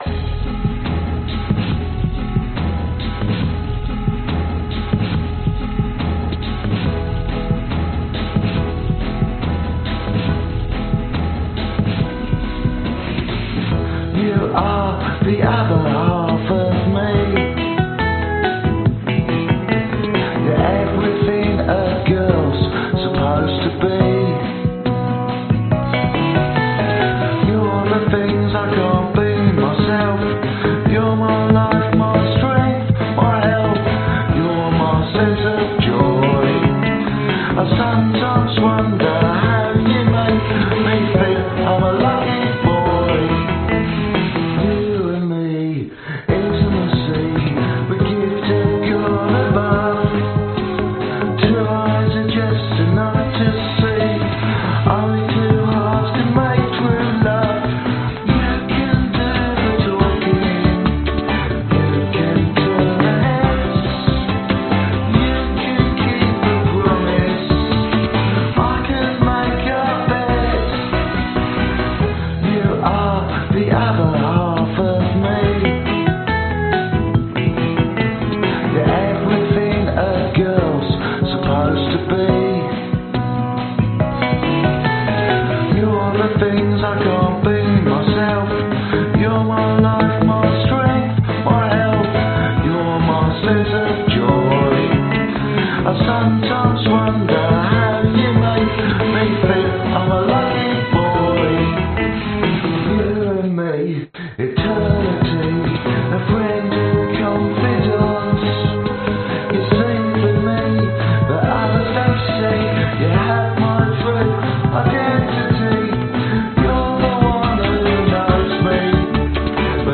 这首曲子里有一些非常垃圾的D50的声音。
Tag: 男声 电子 吉他 贝司 合成器 循环 原声